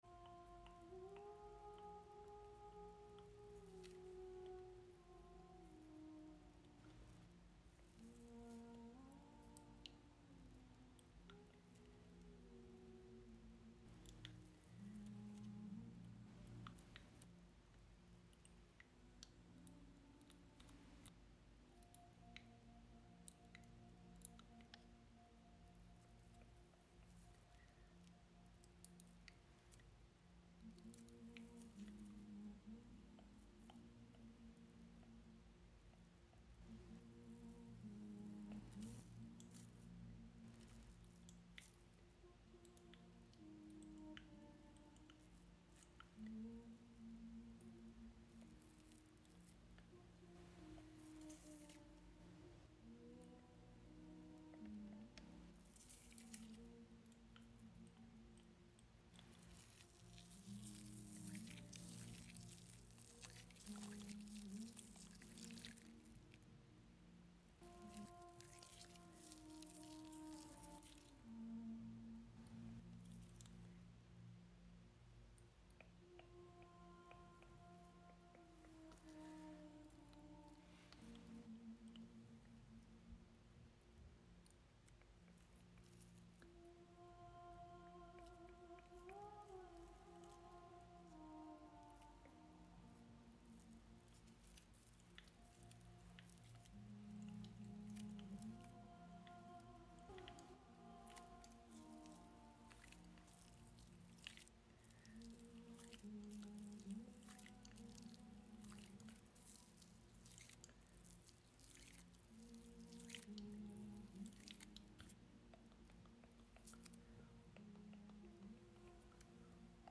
ASMR, foam facial massage, for sound effects free download